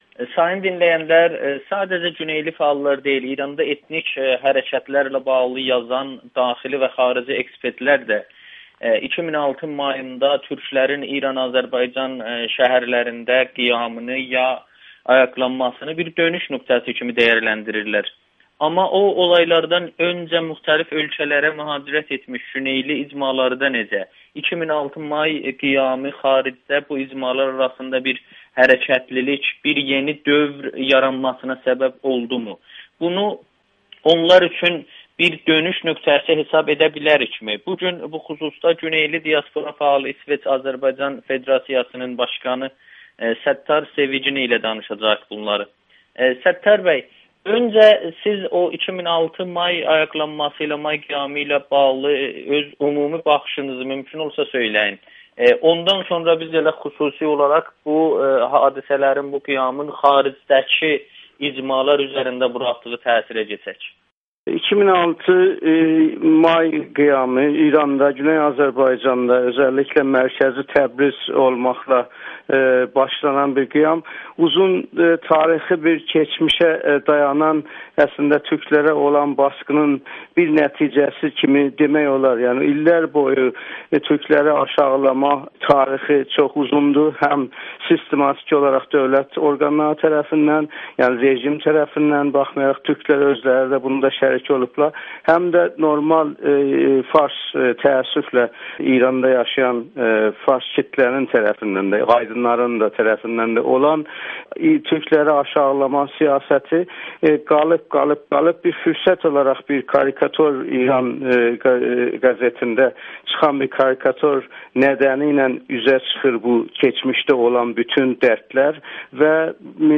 Amerikanın Səsi ilə söhbətdə